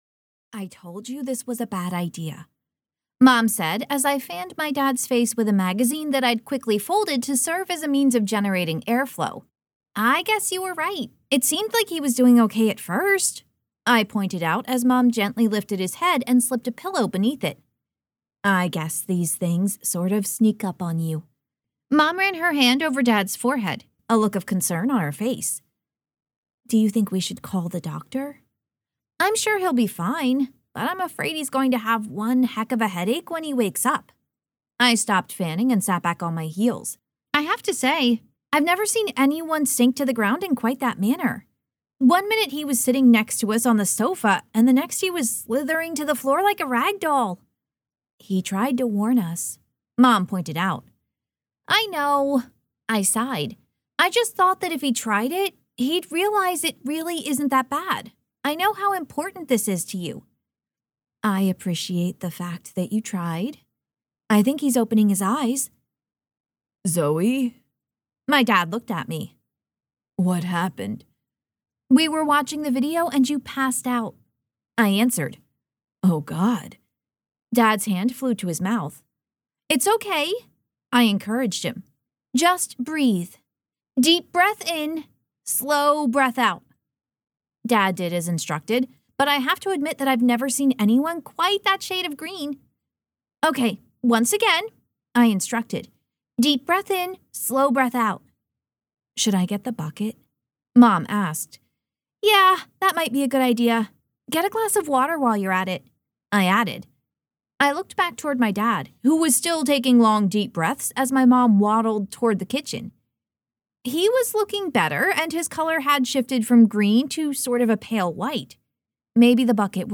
• Audiobook
Book 5 Retail Audio Sample Big Bunny Bump Off (Zoe Donovan Mystery).mp3